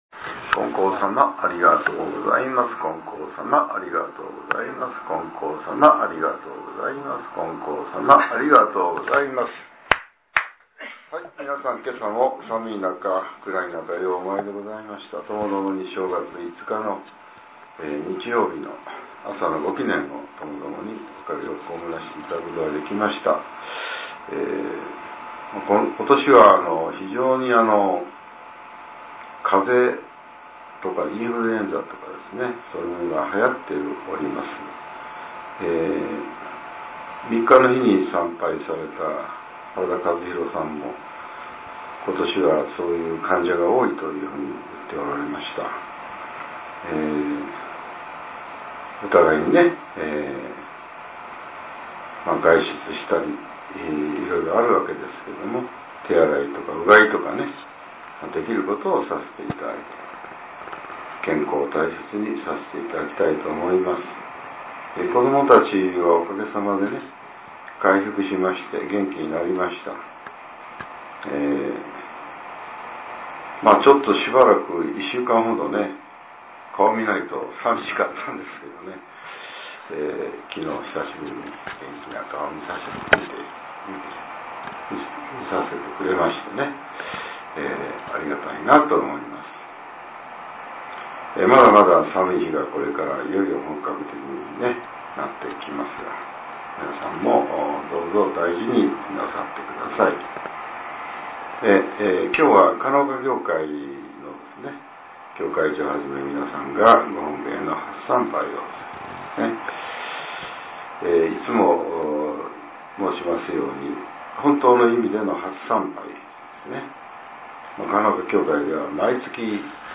令和７年１月５日（朝）のお話が、音声ブログとして更新されています。